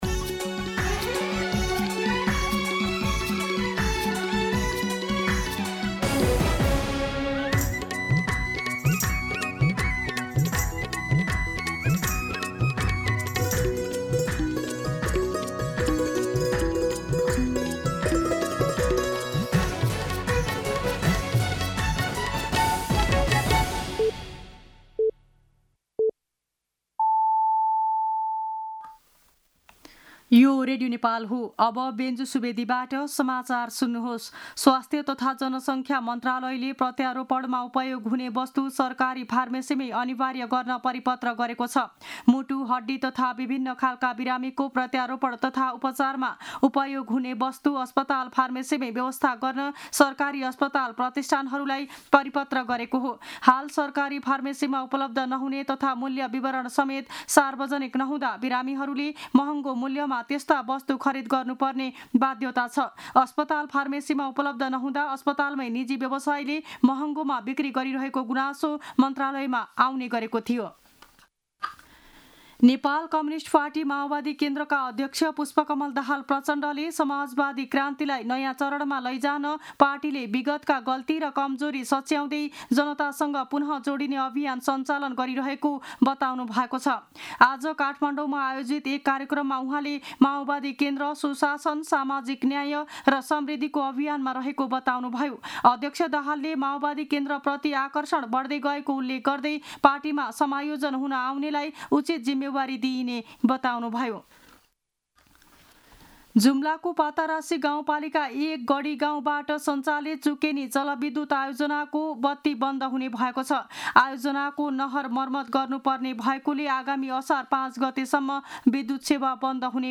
दिउँसो १ बजेको नेपाली समाचार : २७ जेठ , २०८२
1pm-News-02-27.mp3